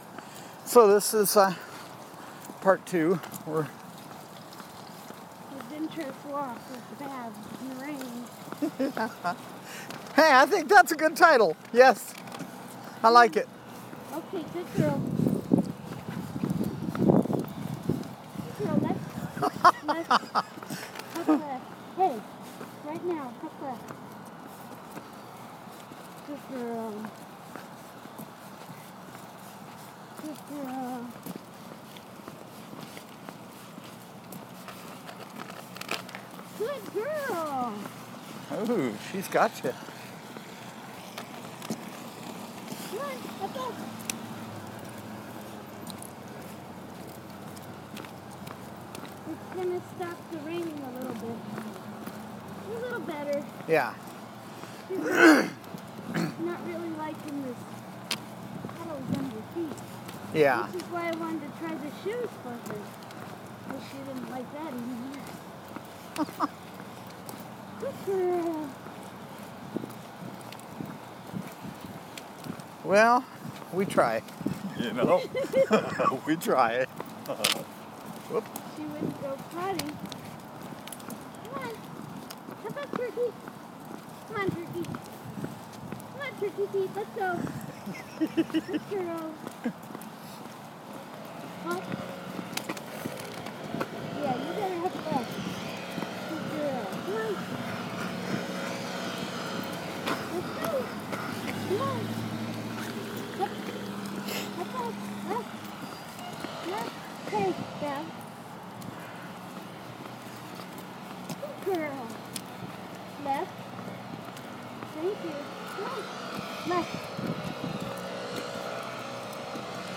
Join us for more fun and adventure as we encounter a leaf blower and a car a long The path.